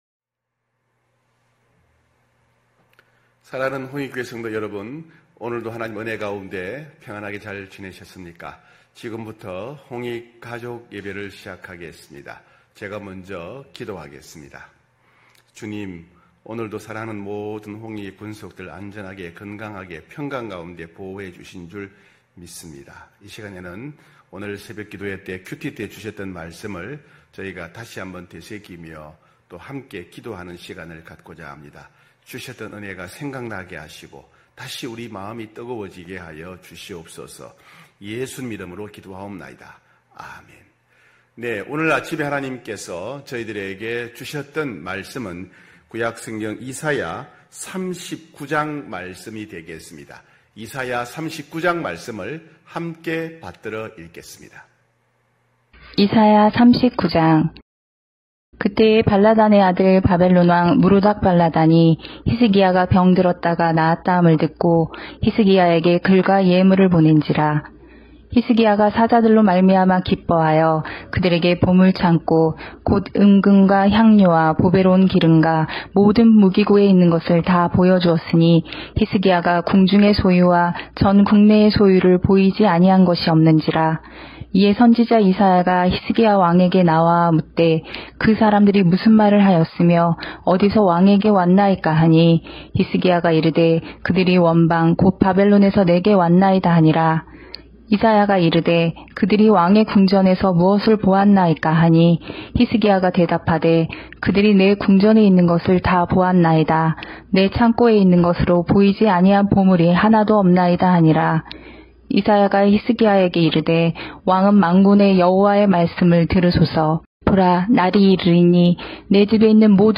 9시홍익가족예배(8월31일).mp3